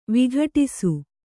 ♪ vighaṭisu